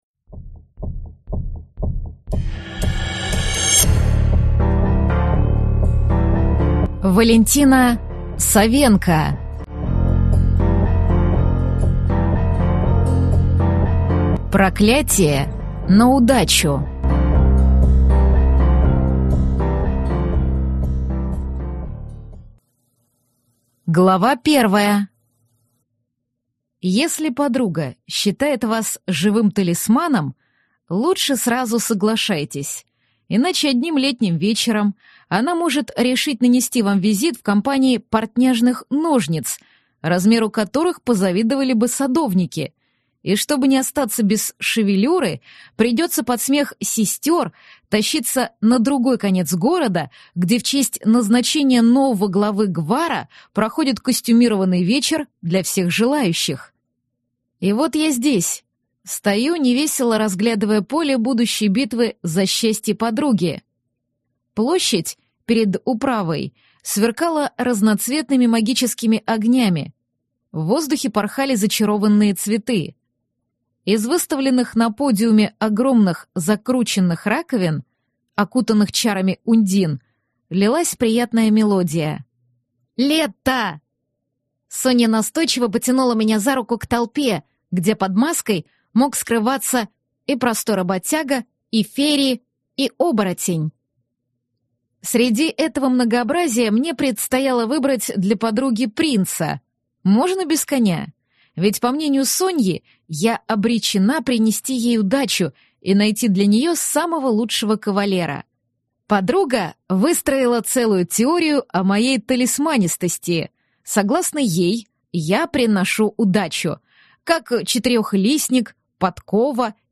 Аудиокнига Проклятие на удачу | Библиотека аудиокниг